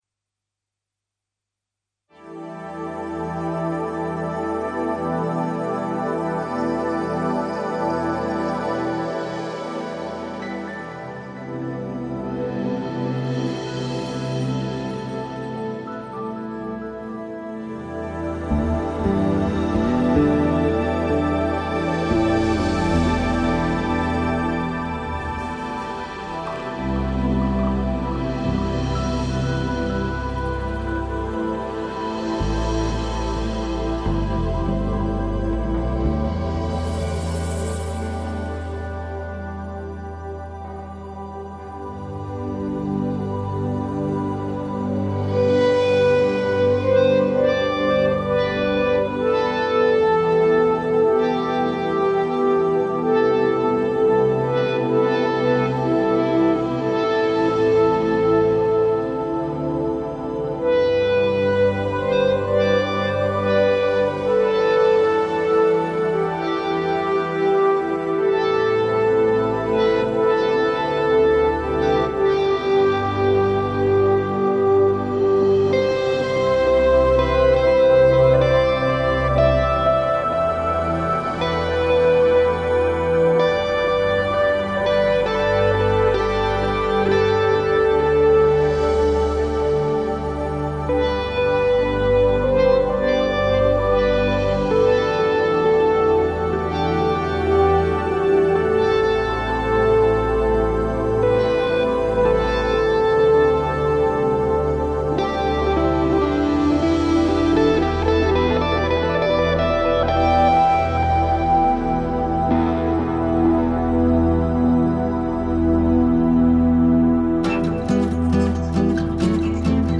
drums
with its lead line picked out over a swirling synth backing